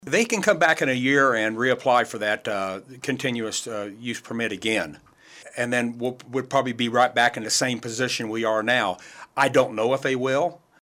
Today he spent time on the KSAL Morning News Extra talking about the reasons he voted to deny a request from the Texas based company, Power Plus from building an energy storage and transmit facility.